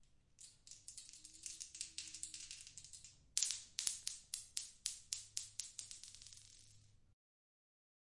骰子被摇动然后滚动
描述：骰子的声音被摇动，然后在瓷砖地板上抛出并滚动。用Zoom H6录音机录制。
Tag: 动摇 板游戏 游戏 缩放 H6 里面 骰子 OWI